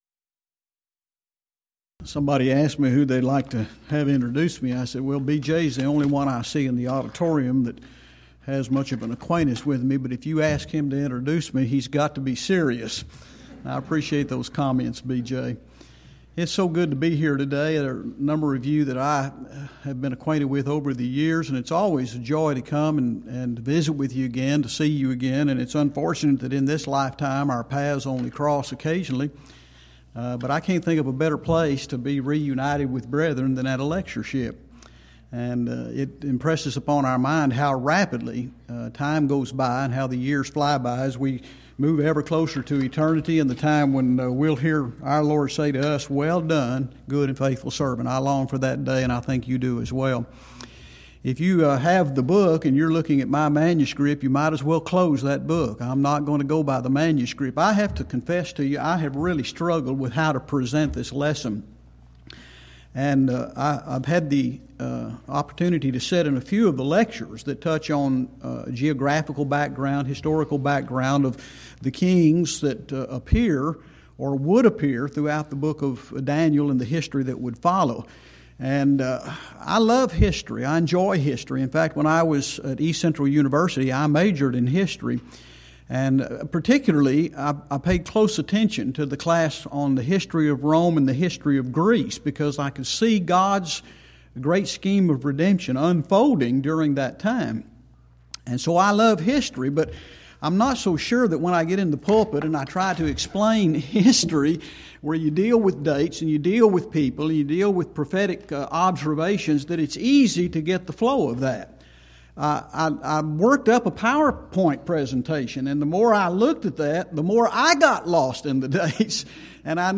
Series: Schertz Lectureship Event: 11th Annual Schertz Lectures Theme/Title: Studies in Daniel